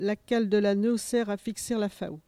Localisation Epine (L')
Catégorie Locution